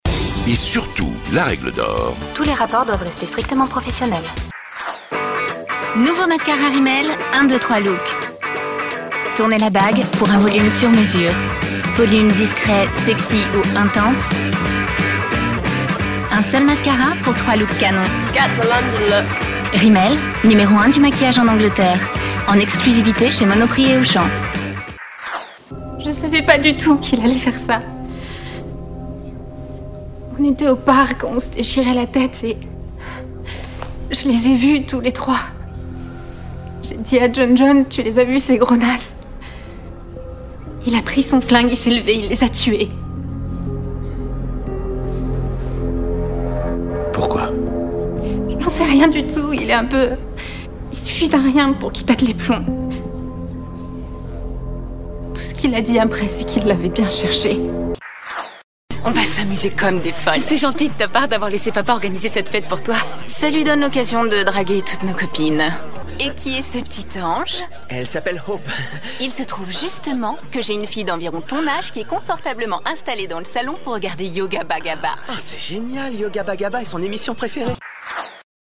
Voix off
Démo voix